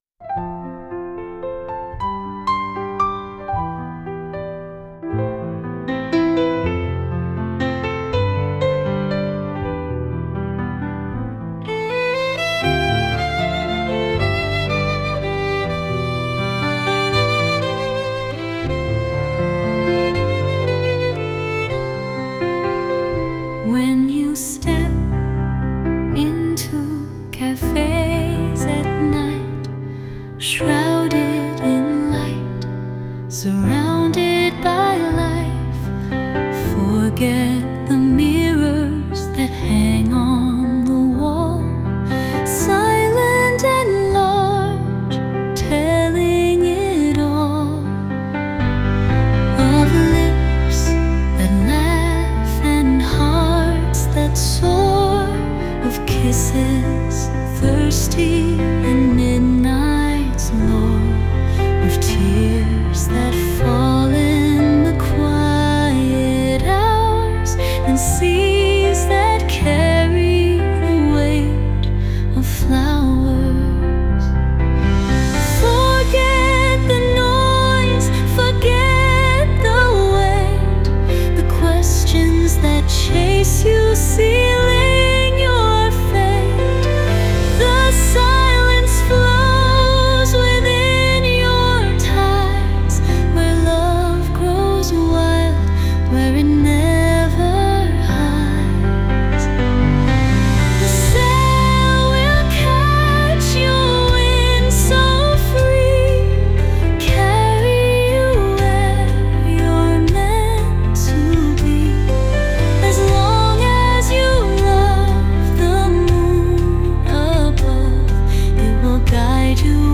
Die musikalische Realisation erfolgt mithilfe von künstlicher Intelligenz, wobei die genauen Quellen den jeweiligen Songseiten hinzugefügt werden.